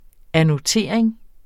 Udtale [ anoˈteˀɐ̯eŋ ]